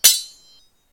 sword_clash.4.ogg